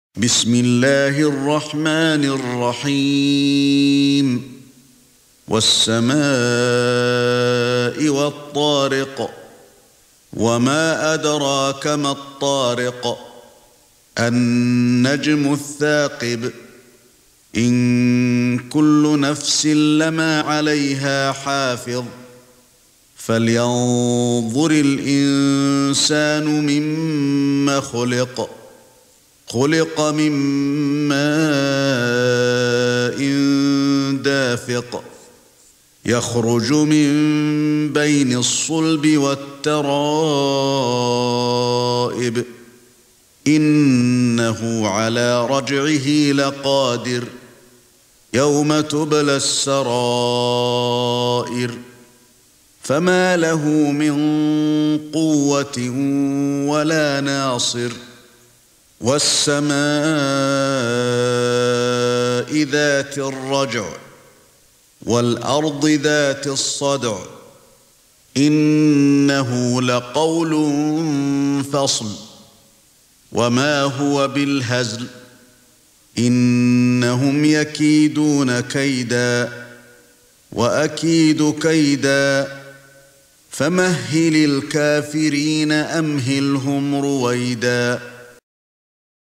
سورة الطارق ( برواية قالون ) > مصحف الشيخ علي الحذيفي ( رواية قالون ) > المصحف - تلاوات الحرمين